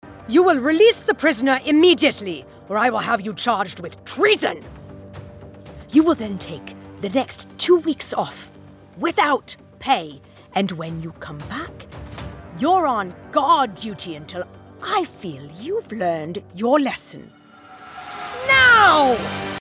Videogame Sample (EN)
Clients describe her sound as engaging, distinct, and easy on the ears - like a trusted guide who knows how to have fun.
Broadcast-quality home studio | Fast, reliable turnaround | Friendly, professional, and directable